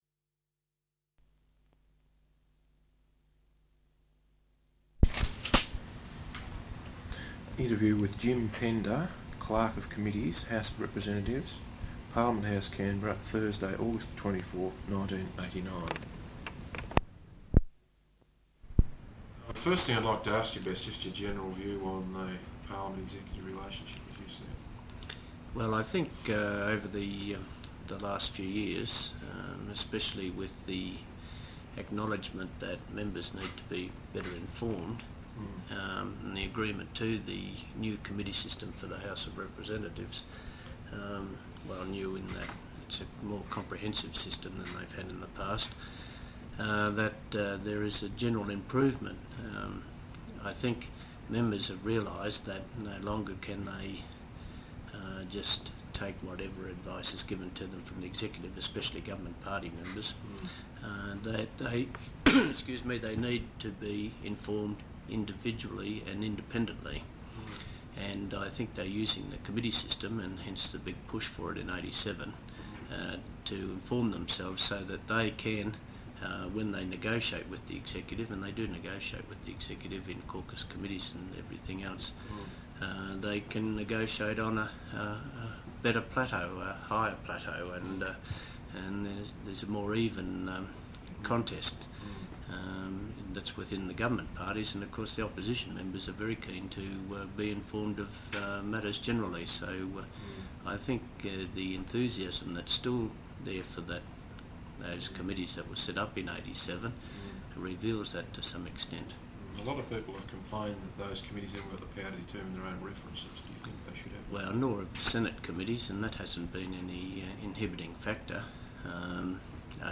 Interview
Parliament House, Canberra.